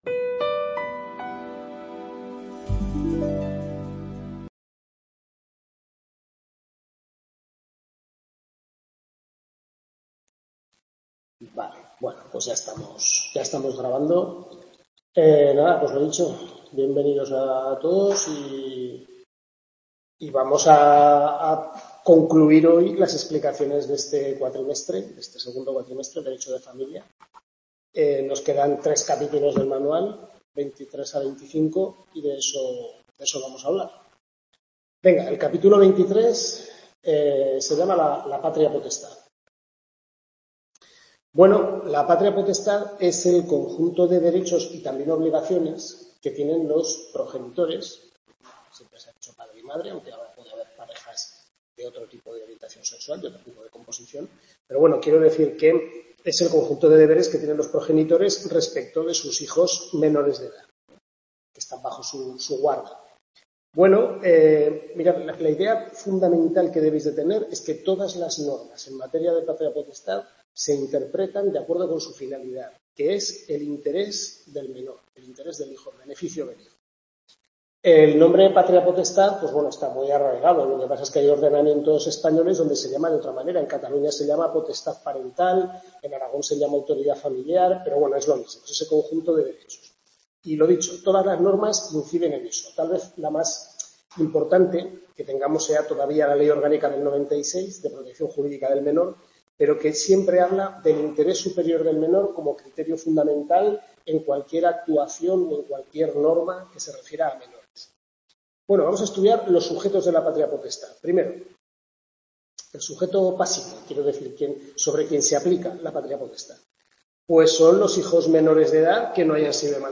Tutoría
Derecho de Familia, centro UNED de Calatayud